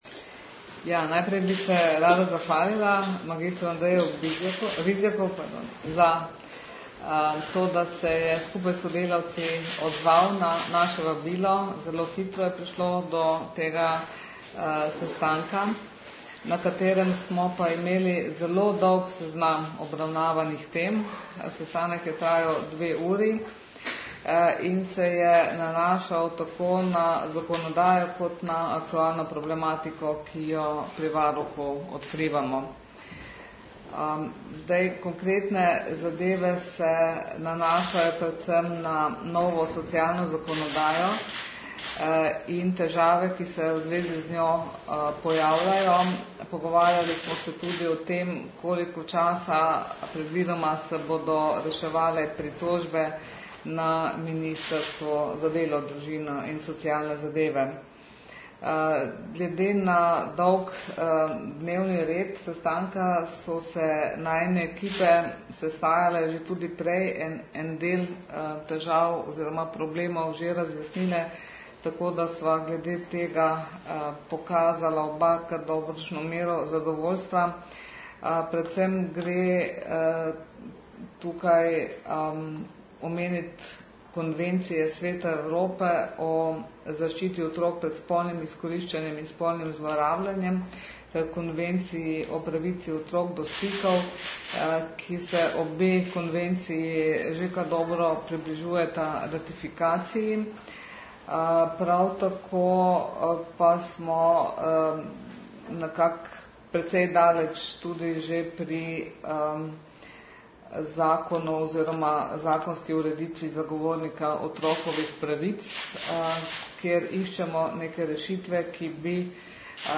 Zvočni posnetek izjave (MP3)